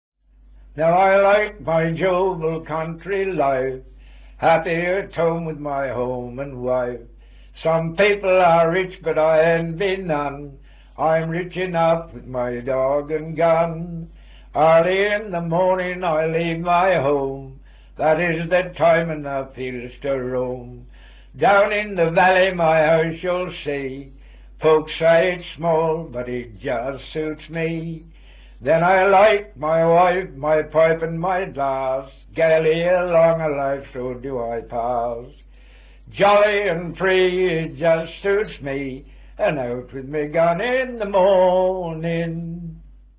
A good mixture of the traditional, music hall and comic songs reflects the variety to be found in a night's pub singing.